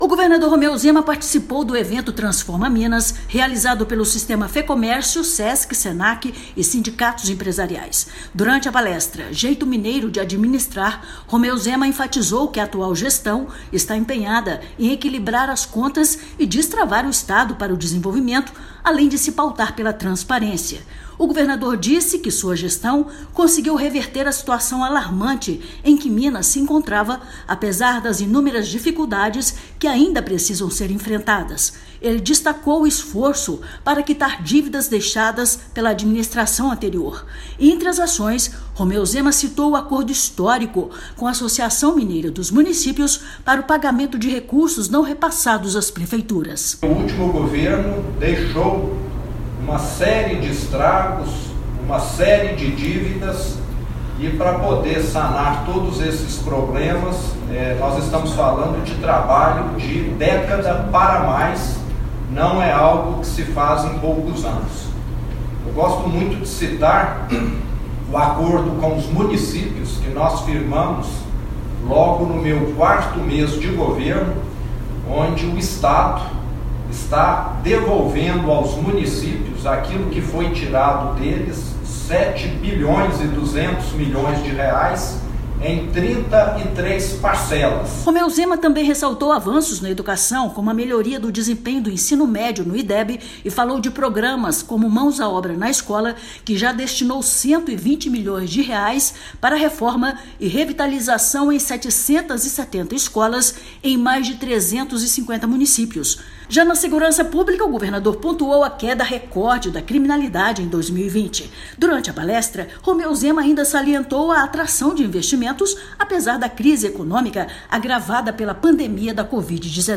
[RÁDIO] Romeu Zema destaca gestão pautada pela verdade e eficiência durante evento da Fecomércio
O governador Romeu Zema participou, nesta quarta-feira (28/4), do evento “Transforma Minas”, realizado pelo Sistema Fecomércio MG, Sesc, Senac e sindicatos empresariais. Durante a palestra “Jeito Mineiro de Administrar”, feita pelo governador, Zema enfatizou que a atual gestão está empenhada em equilibrar as contas e destravar o Estado para o desenvolvimento, além de fazer mais com menos, com eficiência, verdade e transparência. Ouça a matéria de rádio.